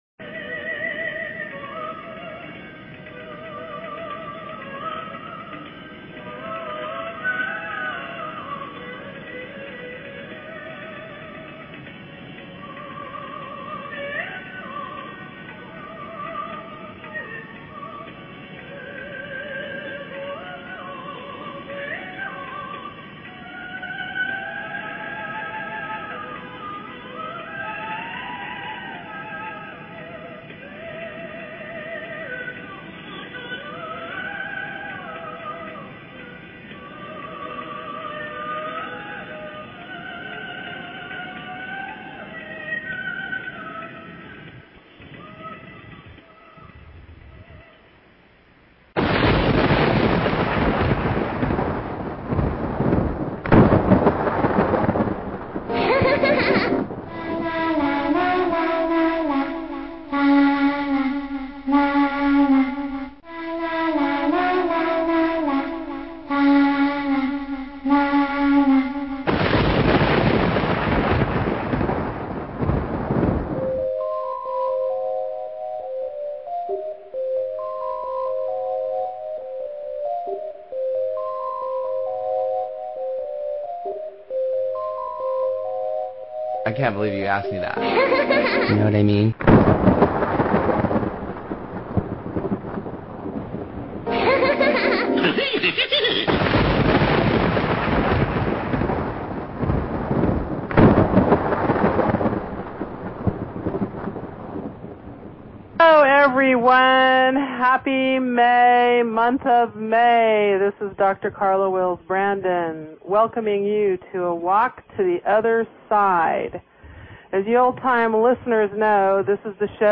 Talk Show Episode, Audio Podcast, A_Walk_To_Otherside and Courtesy of BBS Radio on , show guests , about , categorized as